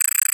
Geometry Dash SFX Library
13232 UI Shuffle 01 0s 0.01 MB